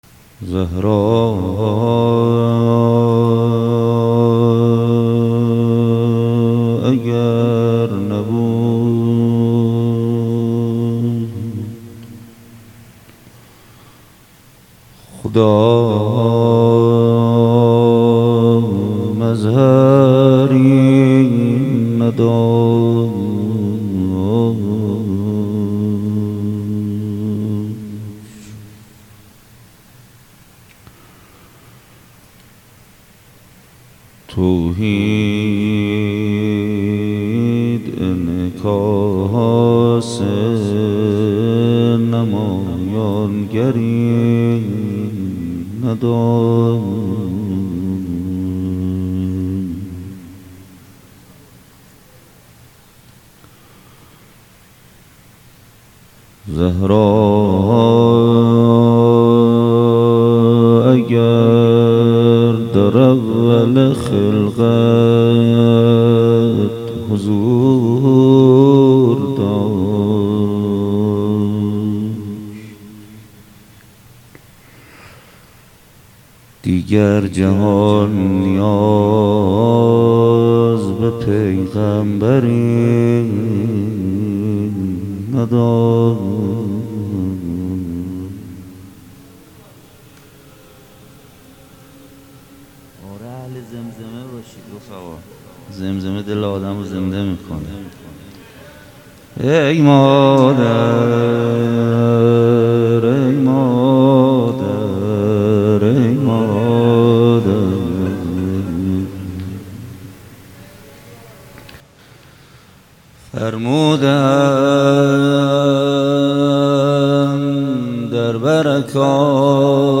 مداحی کربلایی محمدحسین پویانفر | ایام فاطمیه 1442 | هیئت ریحانه النبی تهران | پلان 3